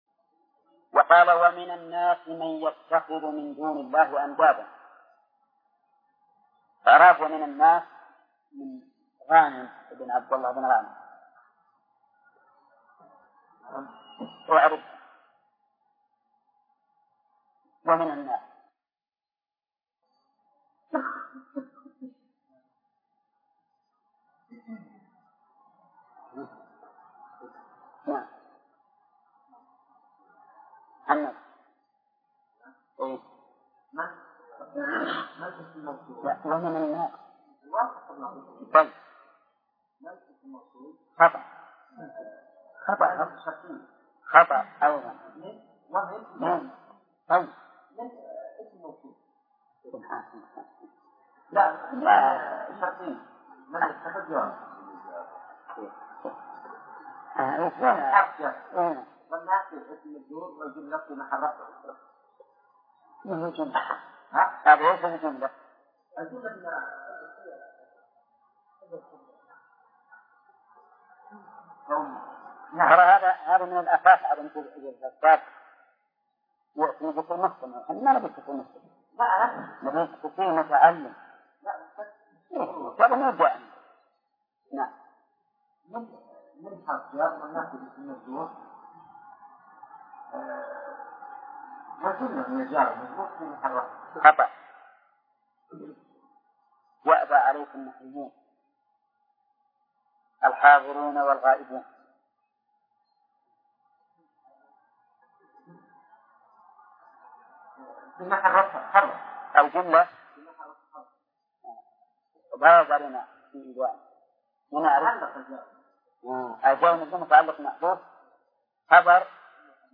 الدرس الثامن من صفحة: (153)، قوله: (قوله تعالى: {ومن الناس من يتخذ من دون الله أندادا ..).، إلى صفحة: (178)، قوله: (باب ما جاء في الرقى والتمائم).